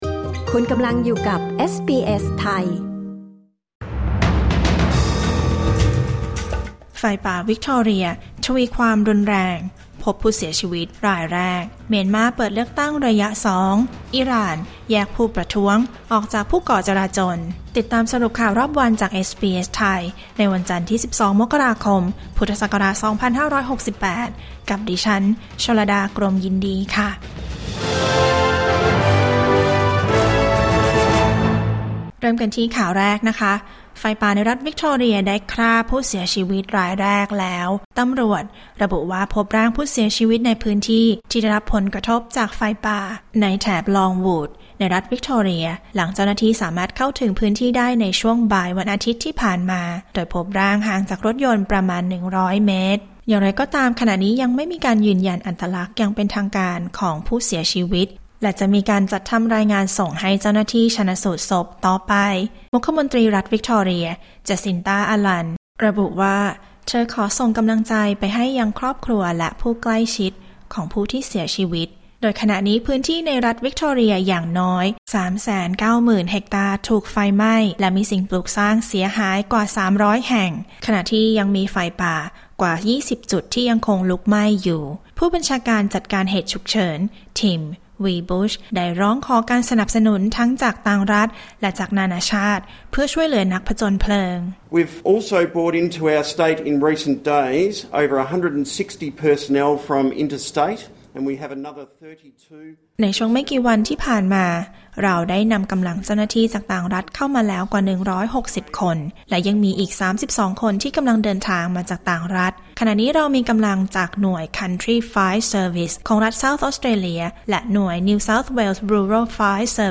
สรุปข่าวรอบวัน 12 มกราคม 2569